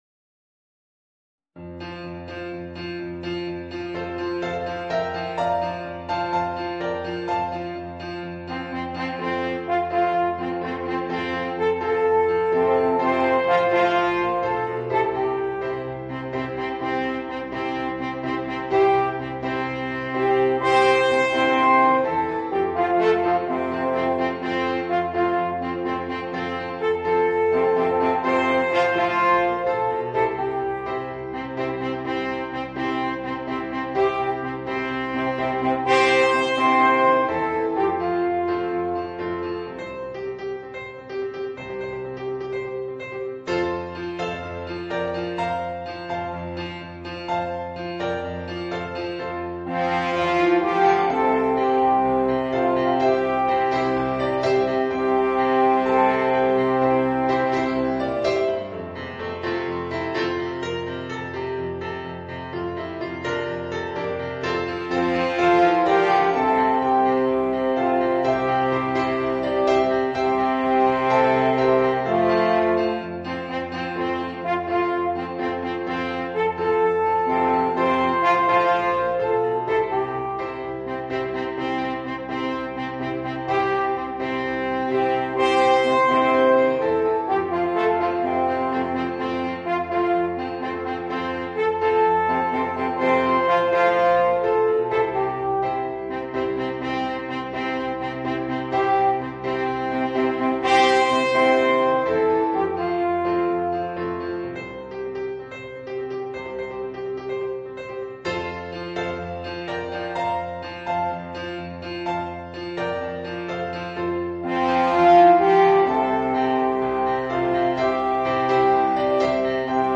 Voicing: 3 Alphorns and Piano